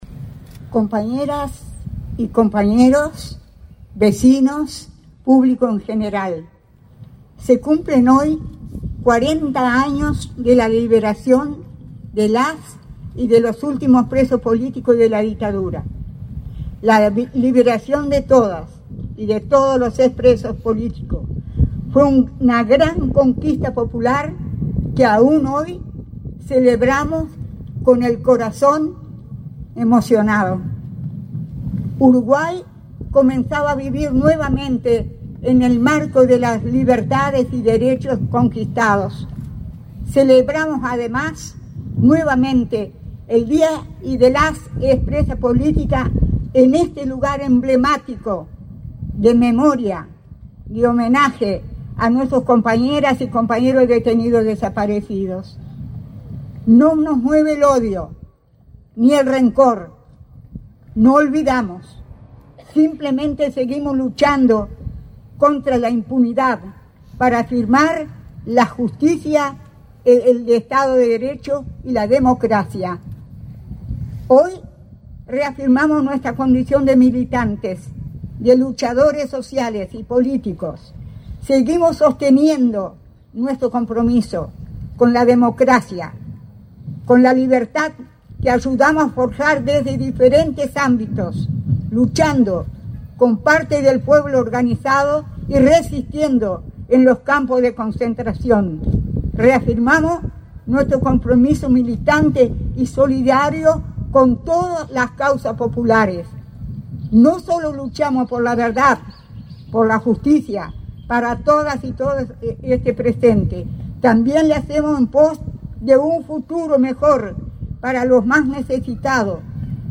Acto en conmemoración de los 40 años de la liberación de los últimos presos políticos en Uruguay
El presidente de la República, profesor Yamandú Orsi, participó, este 14 de marzo, del homenaje a 40 años de liberación de presos políticos en Uruguay